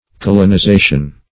Search Result for " kaolinization" : The Collaborative International Dictionary of English v.0.48: Kaolinization \Ka`o*lin`i*za"tion\, n. The process by which feldspar is changed into kaolin.